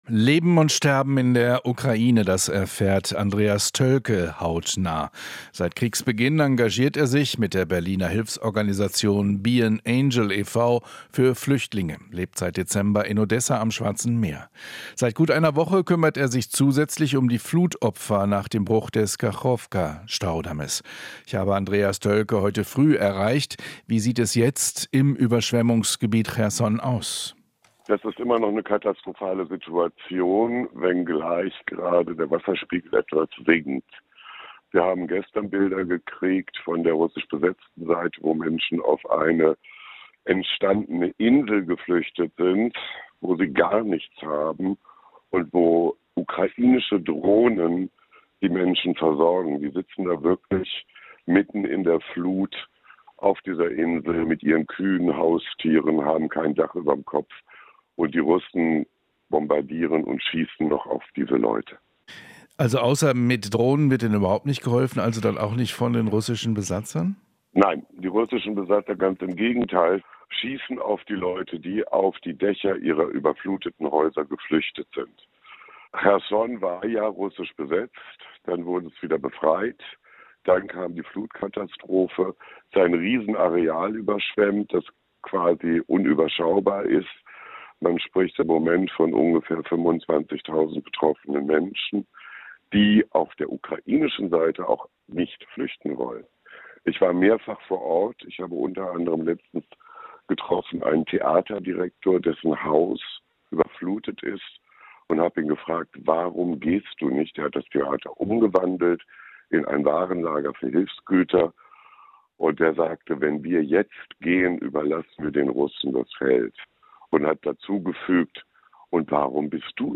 Interview - Berliner Hilfsverein: "Katastrophale Situation in Cherson"